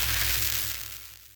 electroshock-pulse-explosion.ogg